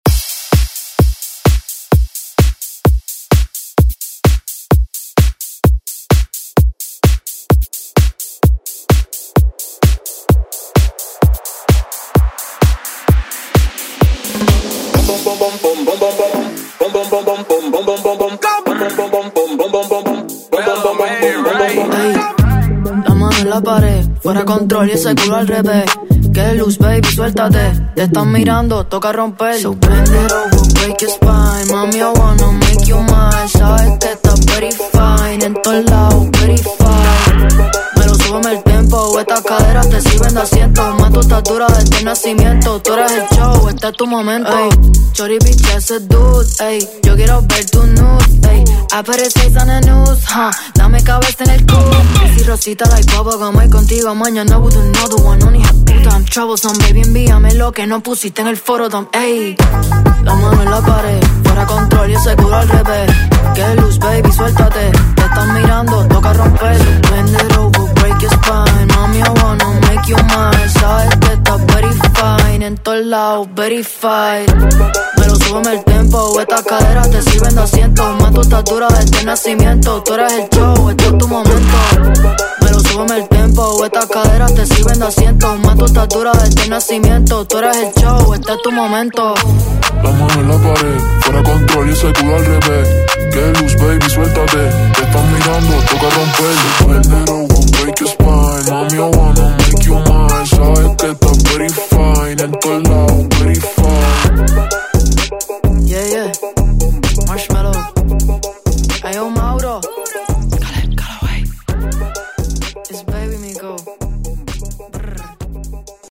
Latin Pop Urban Music Extended ReDrum Dirty 97 bpm
Genres: LATIN , RE-DRUM , REGGAETON
Dirty BPM: 97 Time